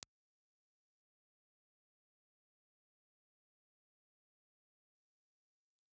Impulse Response File:
Impulse Response file of restored BM5 ribbon microphone
B&O_BM5_IR.wav